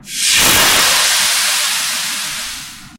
- Действия: облако яда (частицы, круглая текстура, звук oblako-yada.mp3, длительность как у трека), луч света и заморозка со звуками из public/.